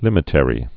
(lĭmĭ-tĕrē)